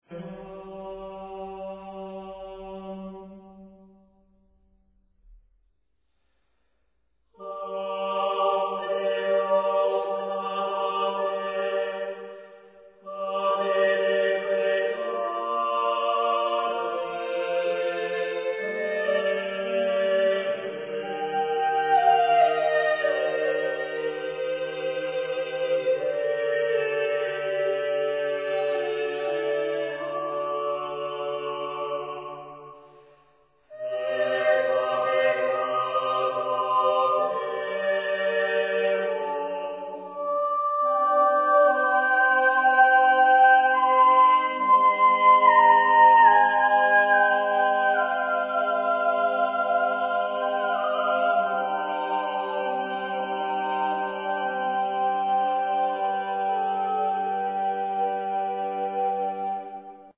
une version "classique" tel que chantée aux environs du 18ème.